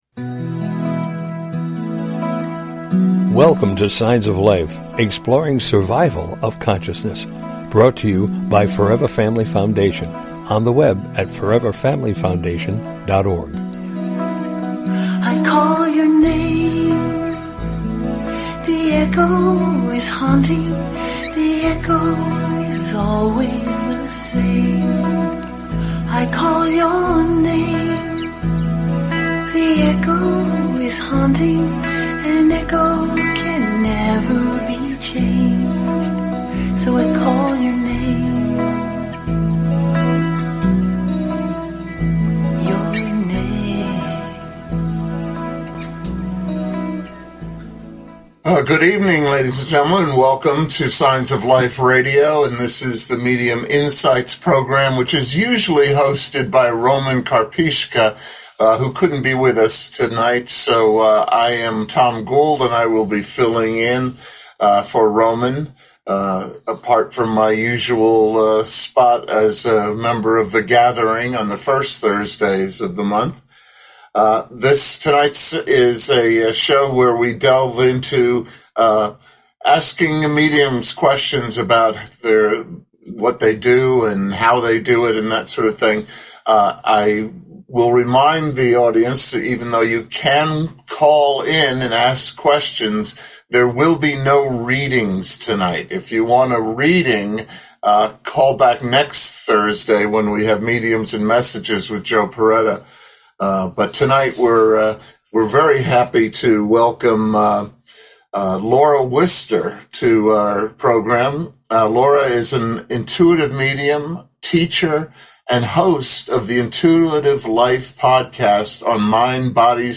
Signs of Life Talk Show
Call In or just listen to top Scientists, Mediums, and Researchers discuss their personal work in the field and answer your most perplexing questions. Topics will include: Mediumship, Near Death Experiences, Death Bed Visions, Reincarnation, Apparitions and Poltergeists, After Death Communication, ESP and Telepathy, Survival of Consciousness, and the list is endless!